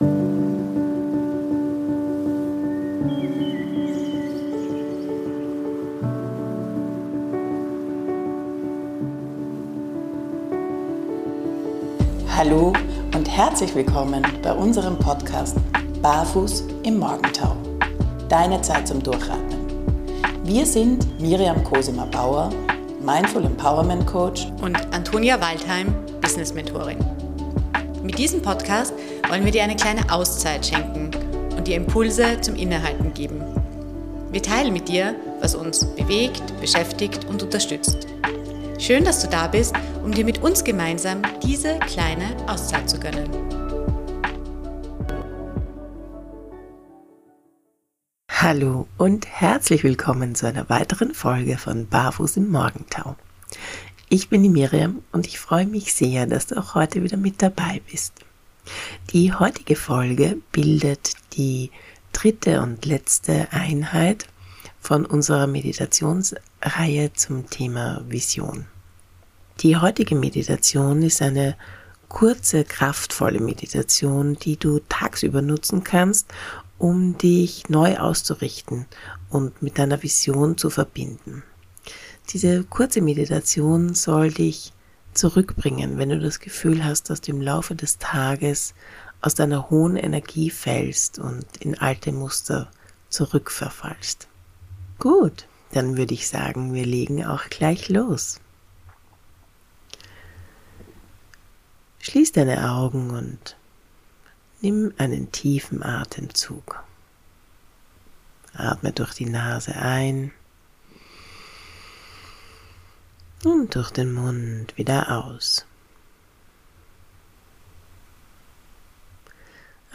Eine 5-Minuten-Meditation für neue Ausrichtung